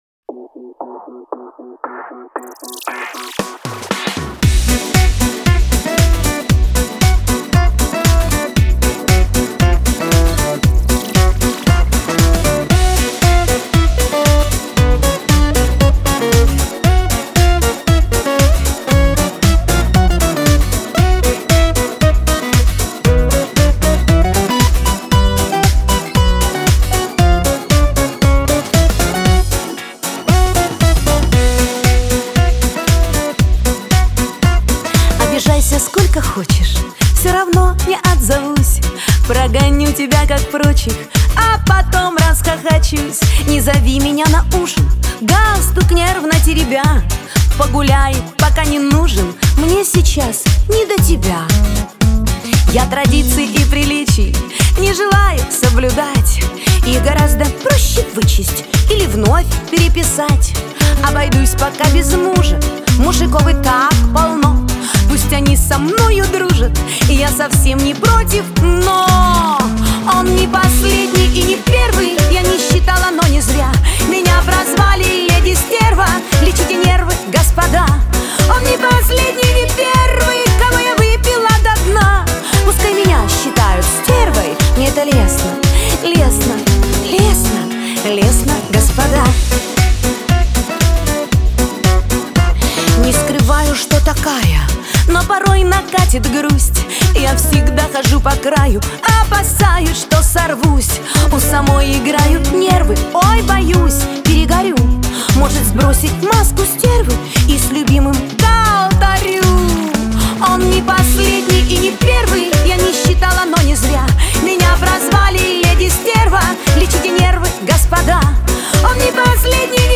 весёлая музыка
dance